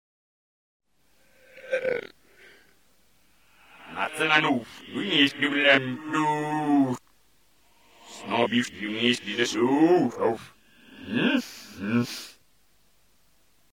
Genere: heavy metal
Rovesciato